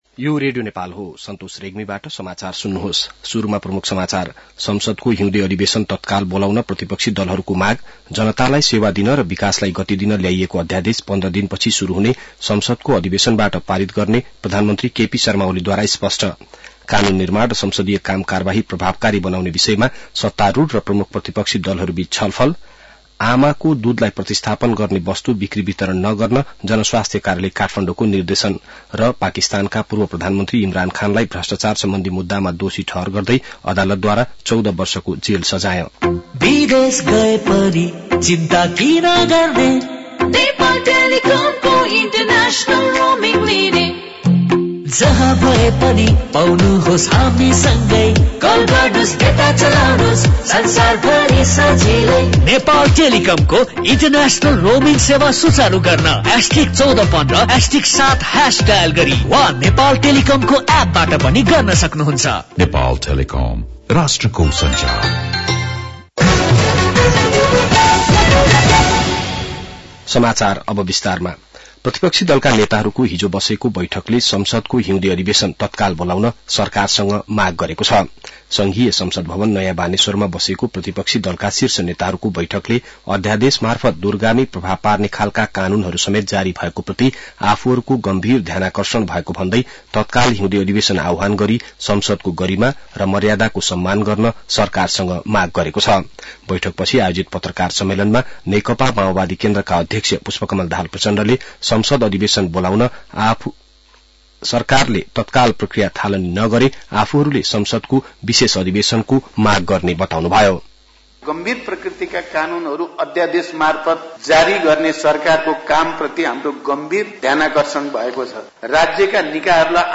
बिहान ७ बजेको नेपाली समाचार : ६ माघ , २०८१